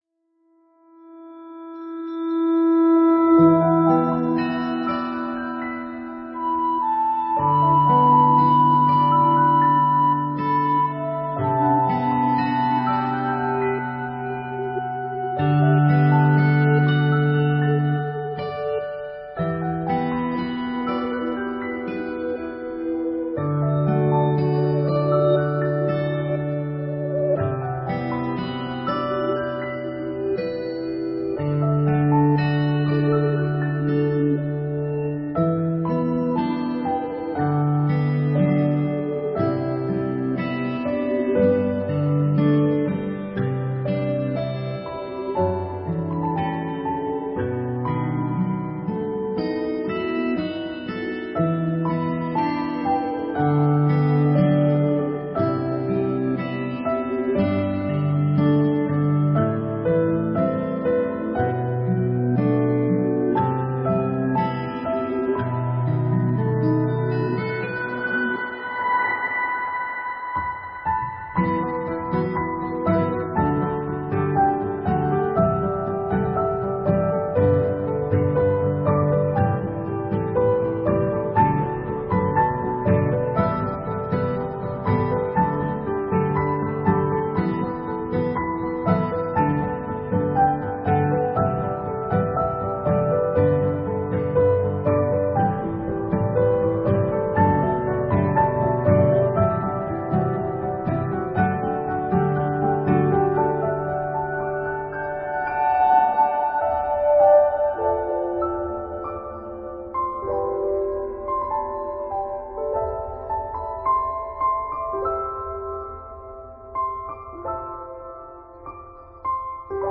BGM:
(BGM是好幾個經典BGM和mix 好聽)